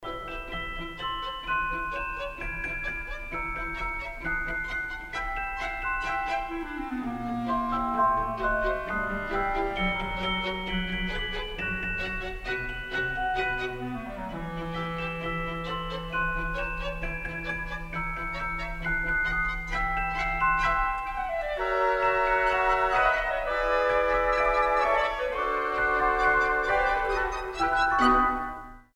мелодичные
спокойные
рождественские